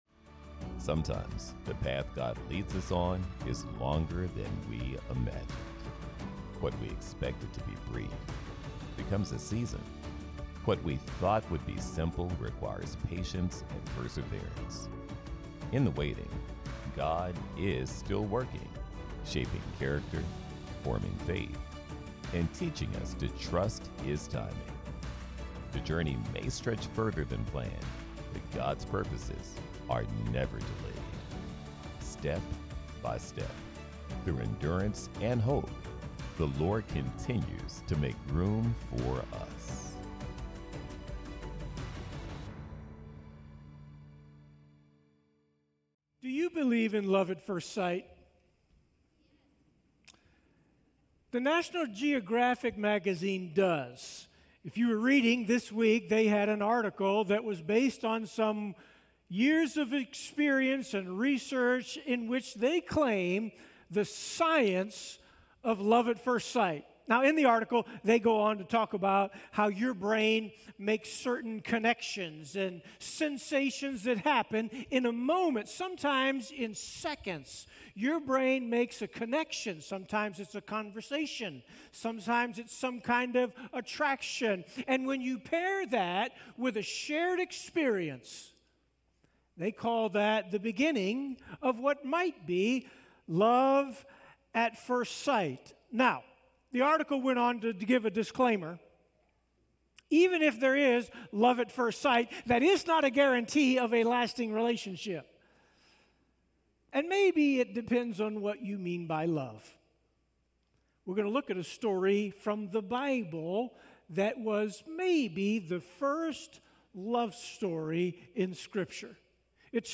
Sermon Listen Worship Jacob's love story with Rachel begins with love at first sight and a seven-year commitment that seemed like only days because of his deep affection.